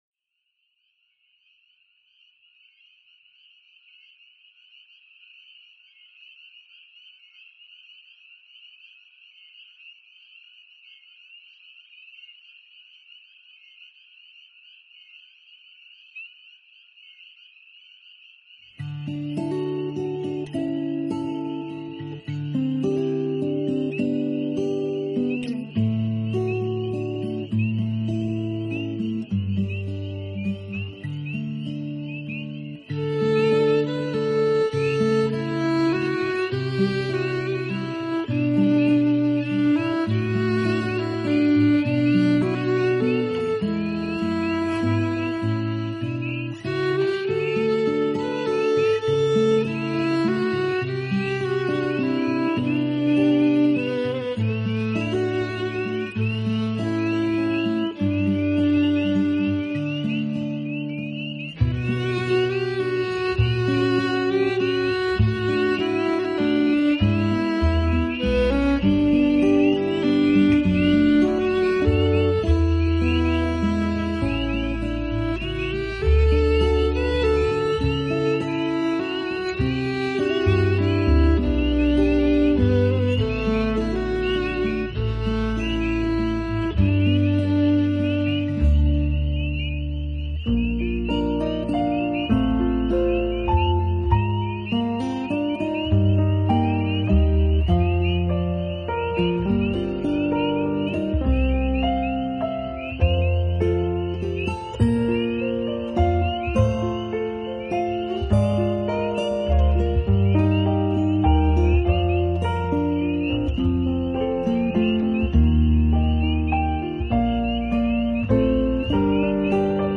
【新世纪纯音乐】
淡淡的美国乡村民谣元素，最大的一个特点便是配器上，用到了美国乡村音乐常用的民
谣小提琴，使得整张专辑散发着一股浓厚的乡土气息，和平而又安详。